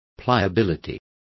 Complete with pronunciation of the translation of pliability.